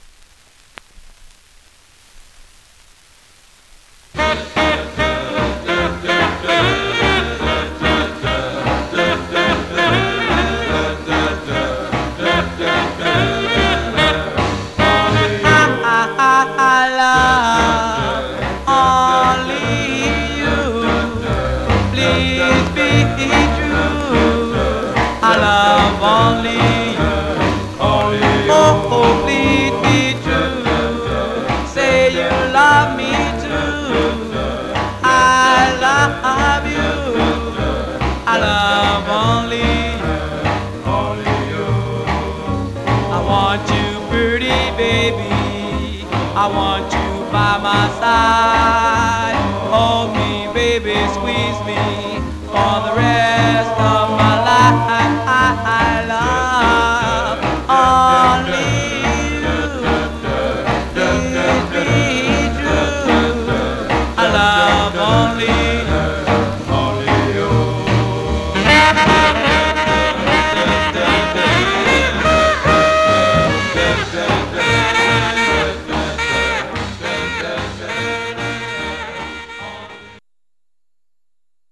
Surface noise/wear Stereo/mono Mono
Male Black Group